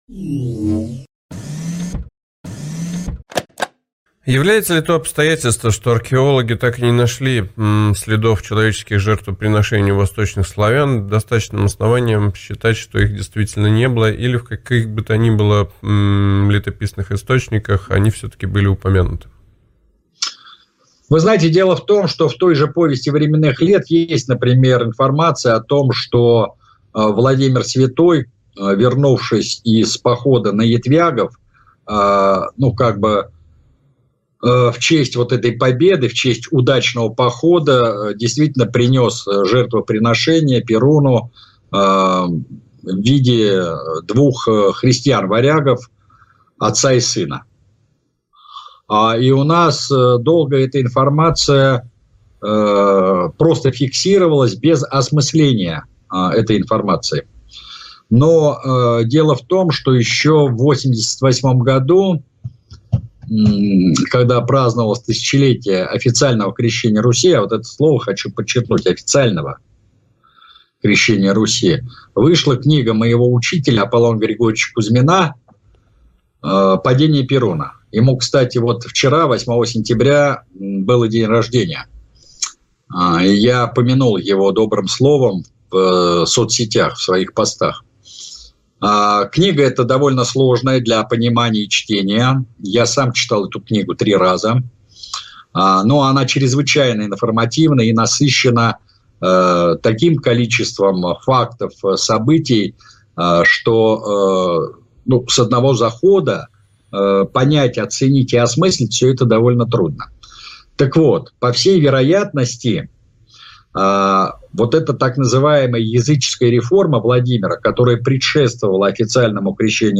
на Радио России в программе «Российский радиоуниверситет» в студии